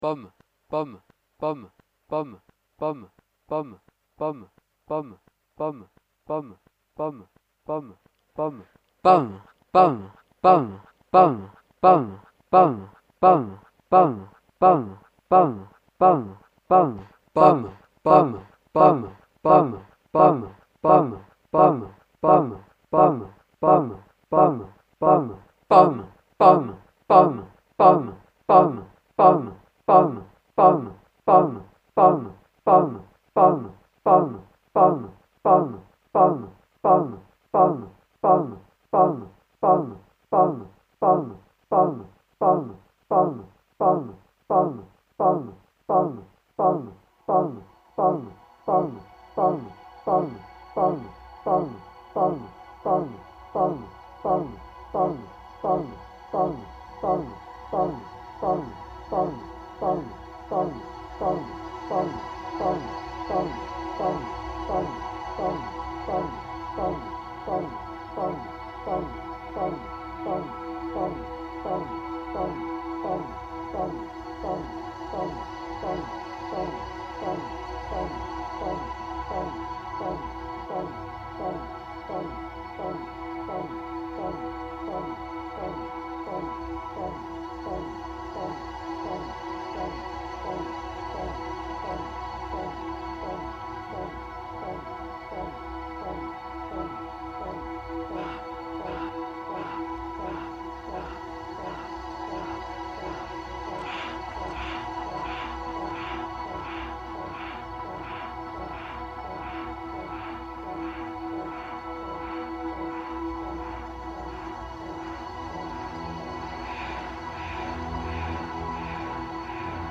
LIVE AT HôTEL POMMERAYE